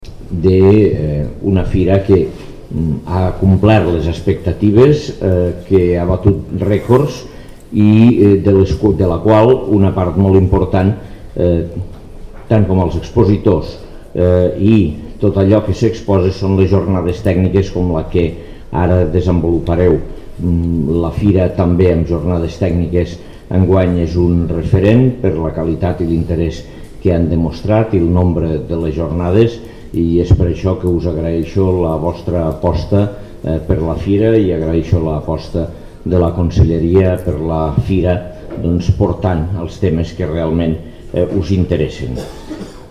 tall-de-veu-de-lalcalde-angel-ros-sobre-la-ix-jornada-de-sanitat-vegetal-a-la-fira-de-sant-miquel